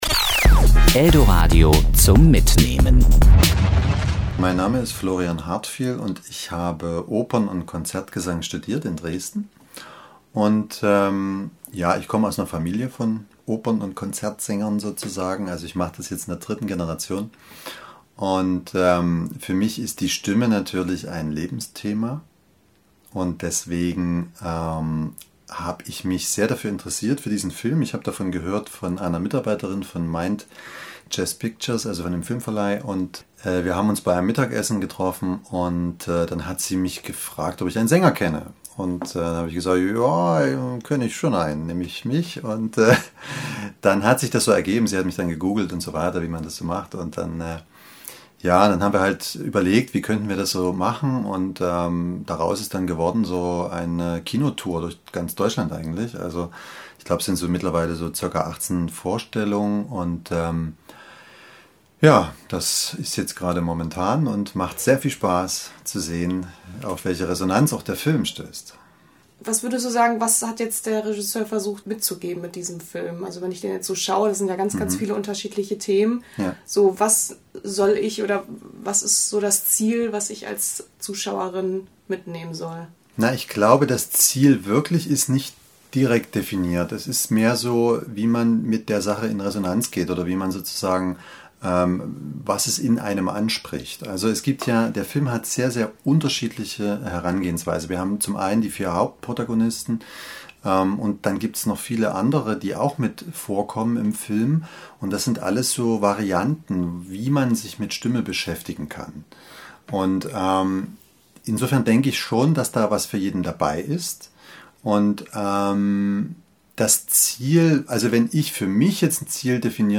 Interview
Serie: Interview